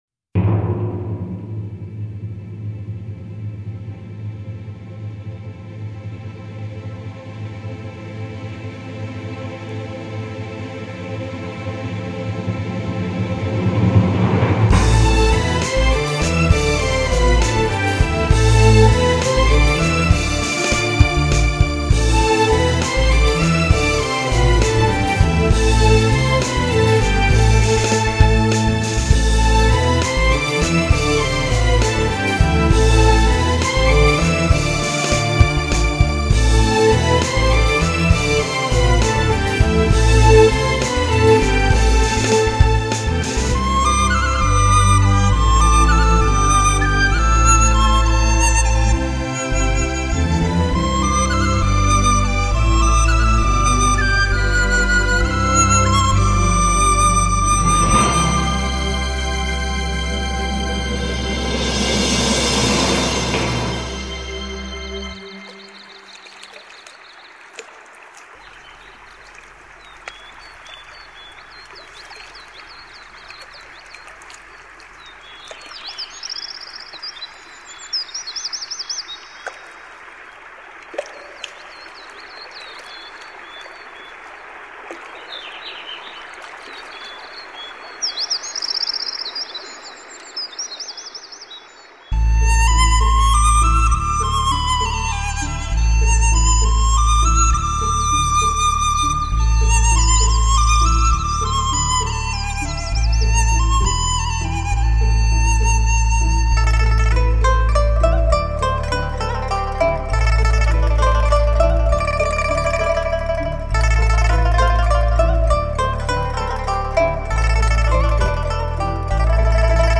大量中国民族乐器原声在一直以电子音色为主导的新世纪音乐中的引入，也是作曲家对新世纪音乐创作的重要突破。
悠扬的旋律汇成音乐的河流，浩浩荡荡，与天籁和鸣，宛若一曲辉煌的天地之交响，令人回味无穷。